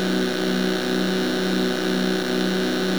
Whine.wav